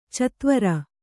♪ catvara